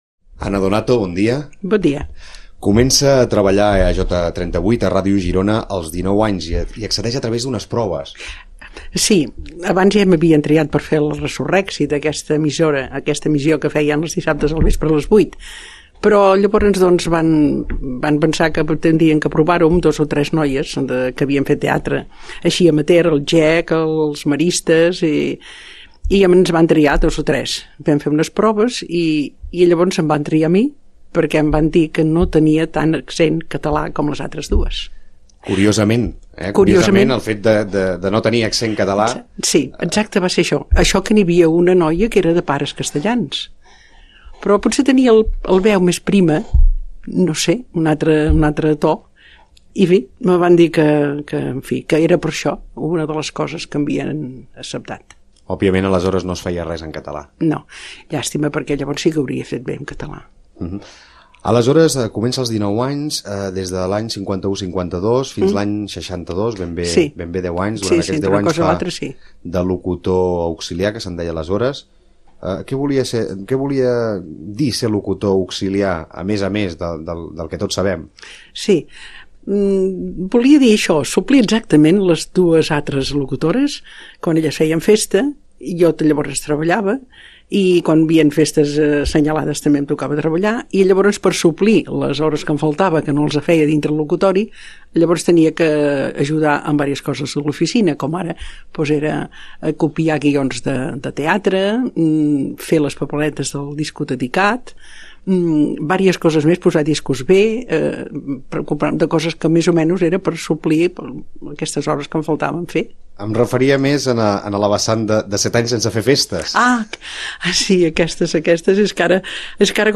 Foto: fotograma de cadena SER Text: fonts diverses Àudio: arxiu sonor de Ràdio Girona
ÀUDIOS Els 65 anys de Ràdio Girona (entrevistada) FONTS Foto: fotograma de cadena SER Text: fonts diverses Àudio: arxiu sonor de Ràdio Girona Prev Següent Anterior Next TORNAR AL BANC DE VEUS